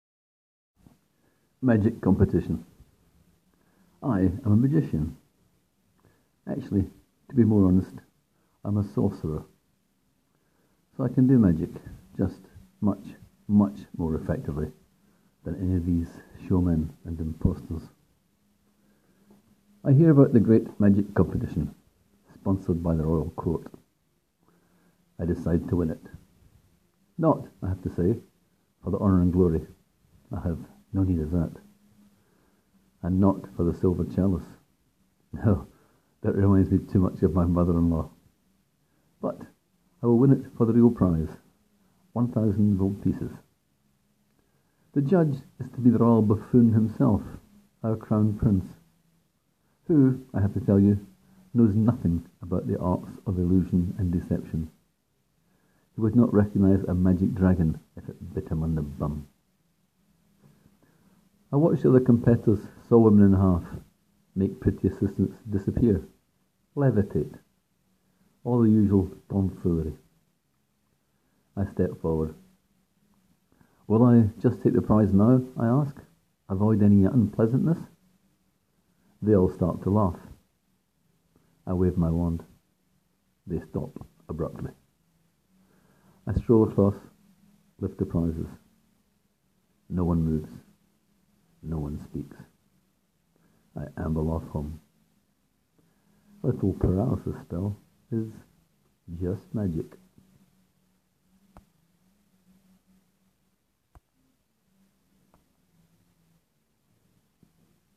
Click here to hear me read the story aloud: